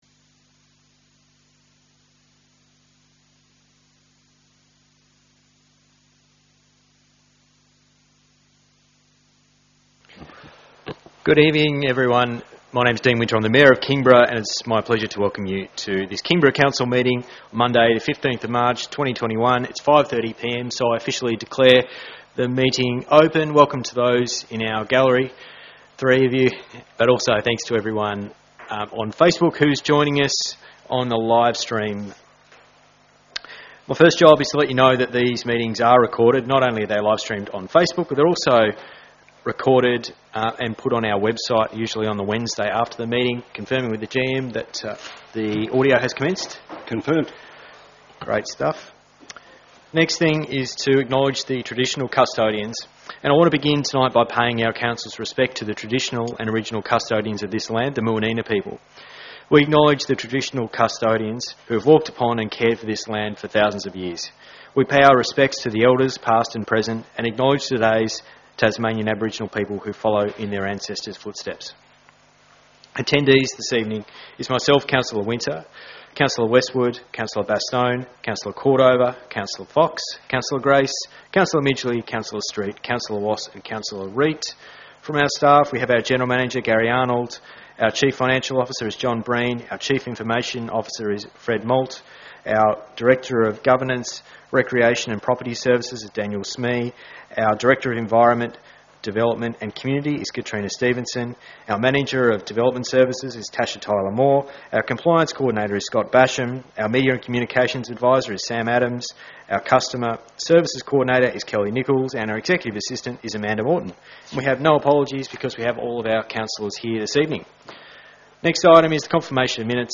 Audio Recording, Council Meeting held on 15 March 2021 |